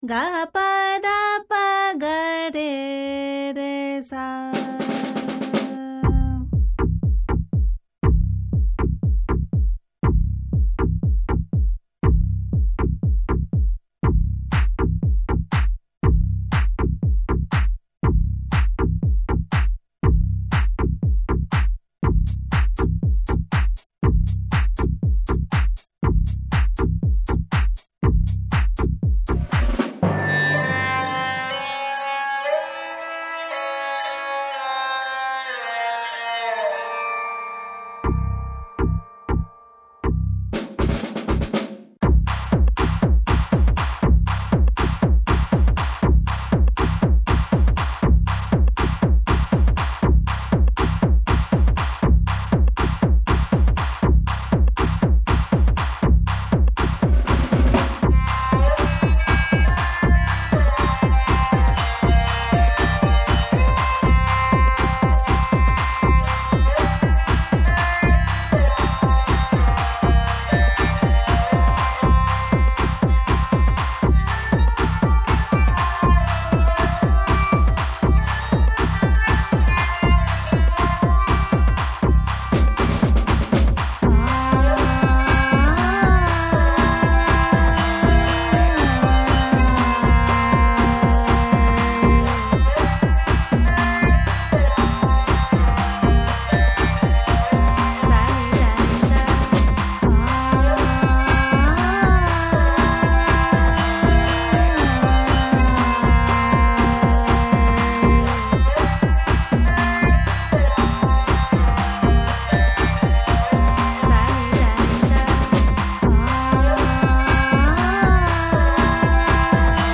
3,4 MB 1999 House